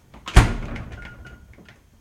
fridgeclose.wav